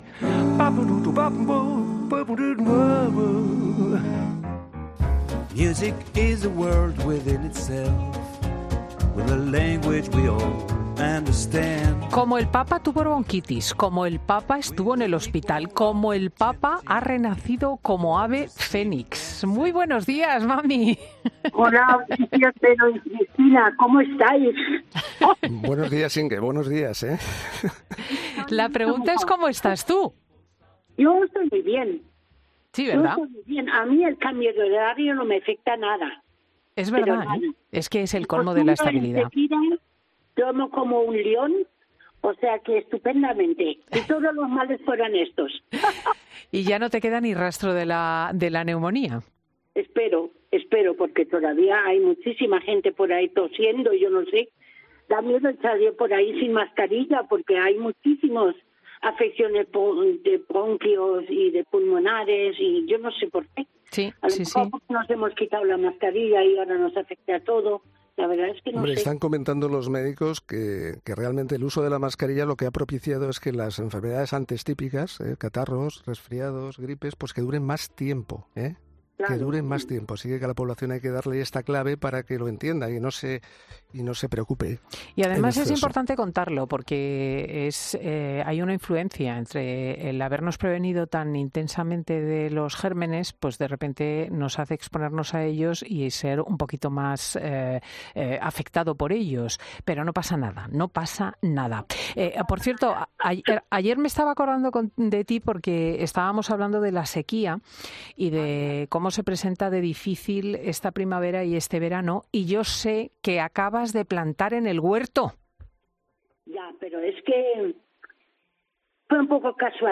Este domingo de Ramos ha pasado por los micrófonos de COPE donde ha reconocido su preocupación por las previsiones de lluvias para los próximos meses: “El otro fin de semana fuimos a Ávila y vi en la Sierra de Gredos poca nieve, lo que signidica que no hay reservas de agua.